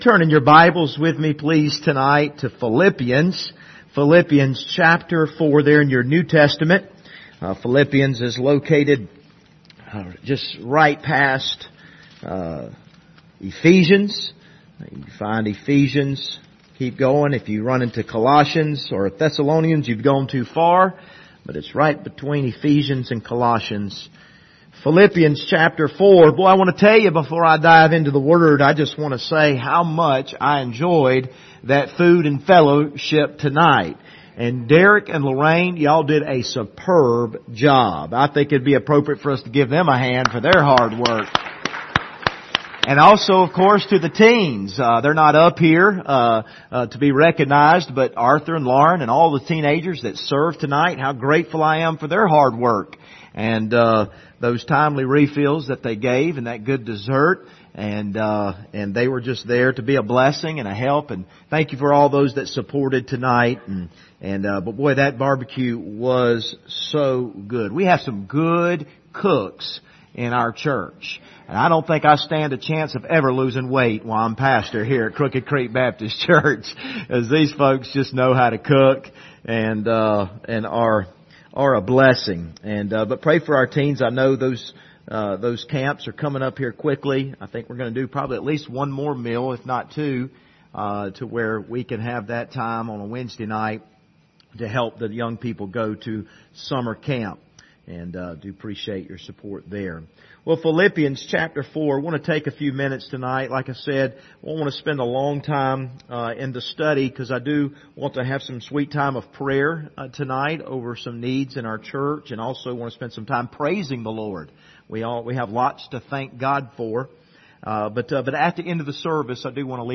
Passage: Philippians 4:8 Service Type: Wednesday Evening